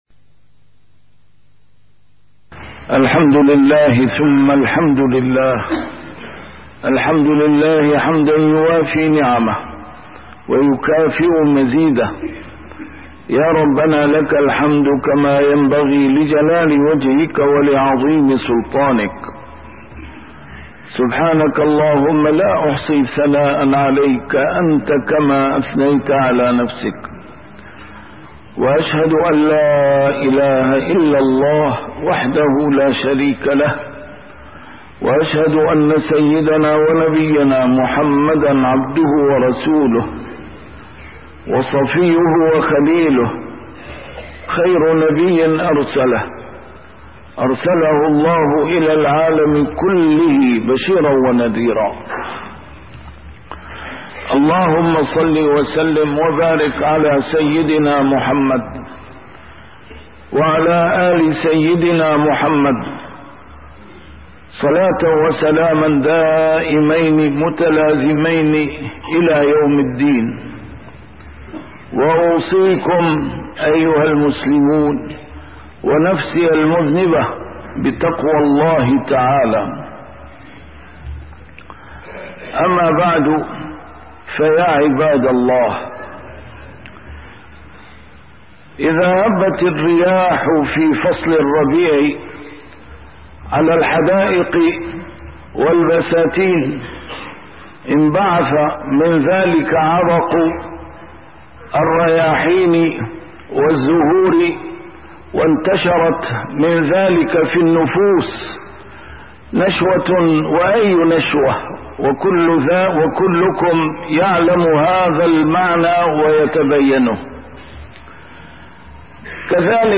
A MARTYR SCHOLAR: IMAM MUHAMMAD SAEED RAMADAN AL-BOUTI - الخطب - دلائل محبة النبي عليه الصلاة والسلام وثمراتُها